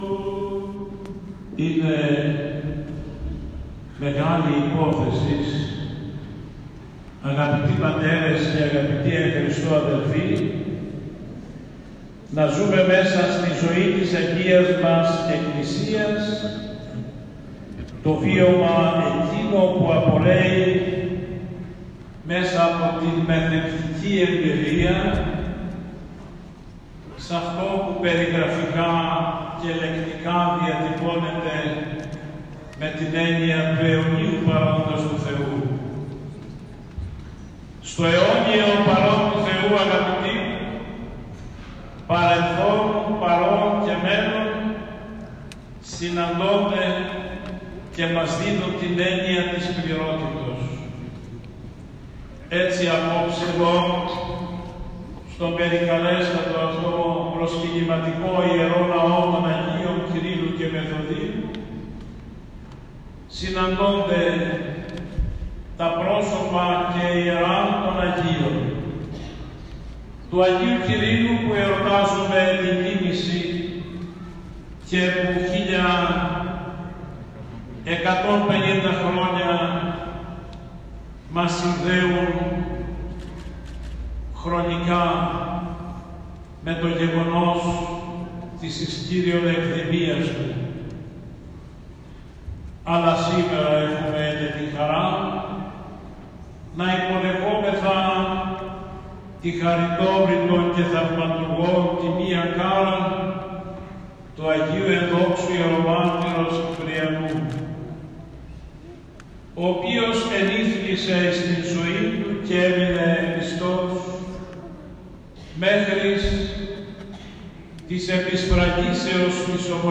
Η υποδοχή έλαβε χώρα στα προπύλαια του Ιερού ναού από τον Σεβασμιώτατο Μητροπολίτη Λαγκαδά Λητής και Ρεντίνης κ. Ιωάννη κατόπιν σεπτής προσκλήσεως του Παναγιωτάτου Μητροπολίτου κ. Ανθίμου.
Ομιλία-λαγκαδα-1.m4a